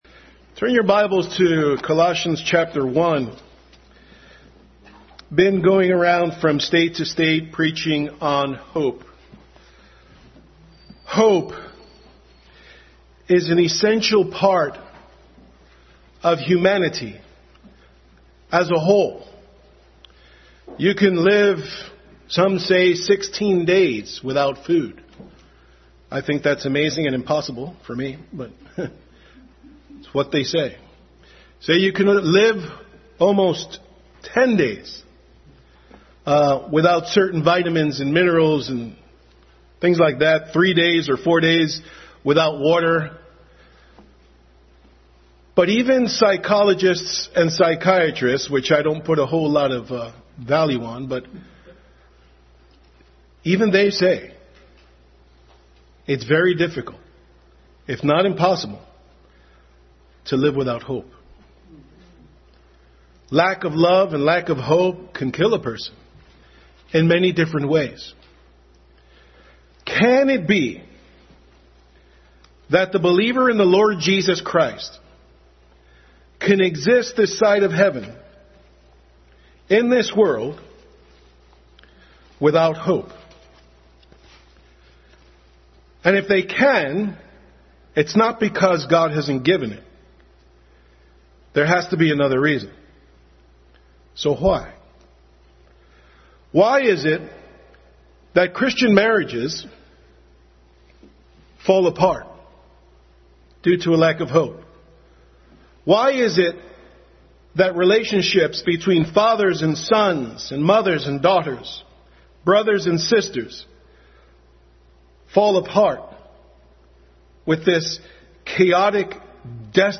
Hope Passage: Colossians 1:1-29, John 13:14-15, 34-35, Hebrews 4:12, Philippians 4:4-7, 2:1-8 Service Type: Family Bible Hour